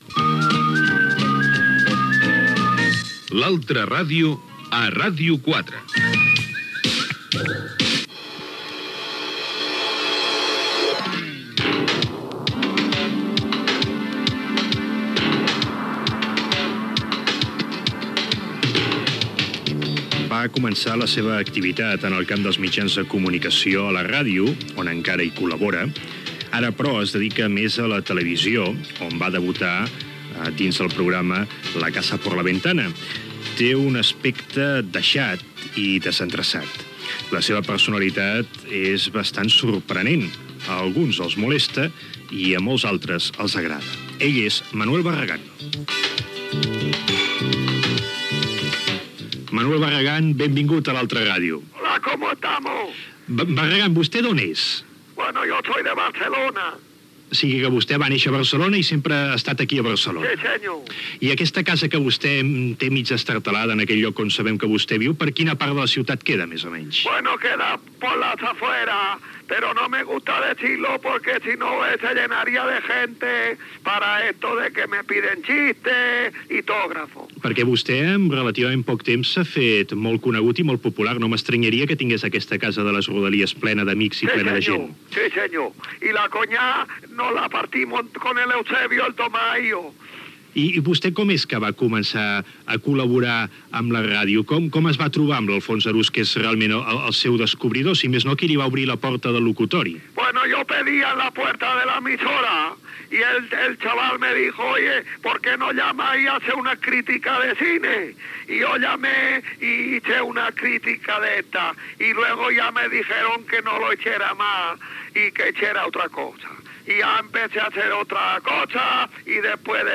Indicatiu del programa, presentació i entrevista
Divulgació